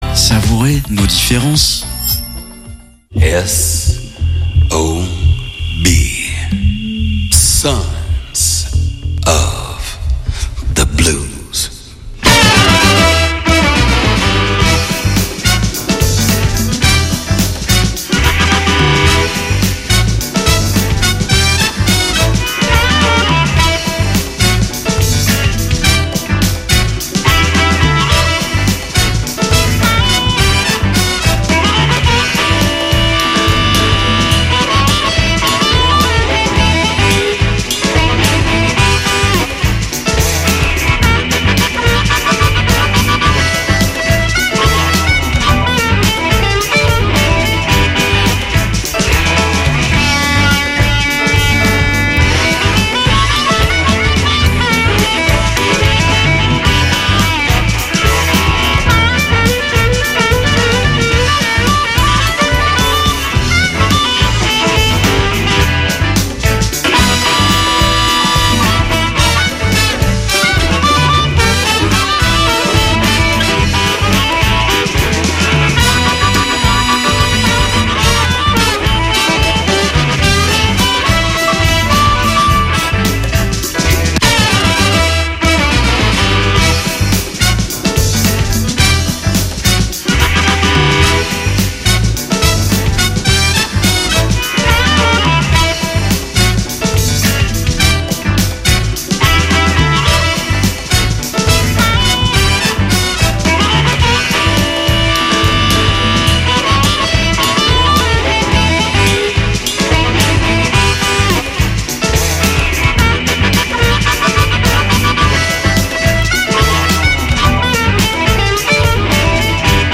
Puisque nous sommes tous des fils et filles du blues, il est bon de se retrouver chaque jeudi à 21H pour 1H de blues d’hier, d’aujourd’hui ou de demain.I